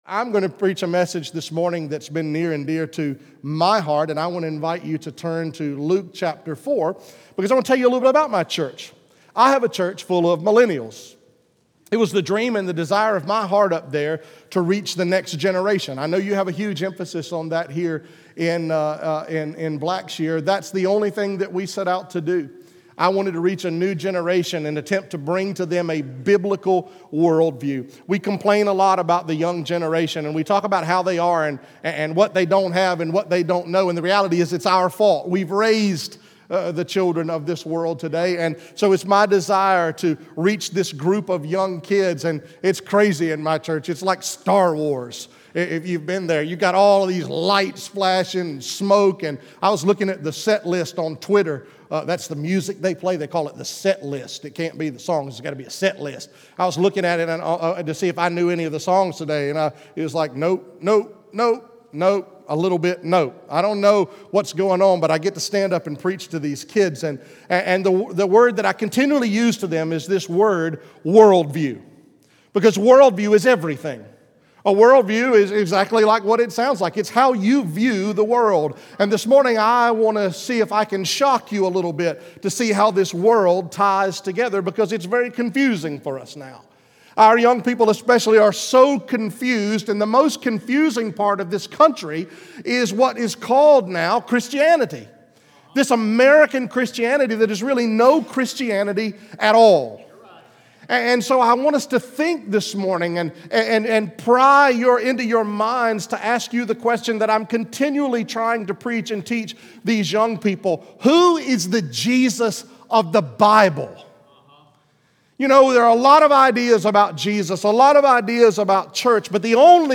From the morning worship service on Sunday, July 7, 2019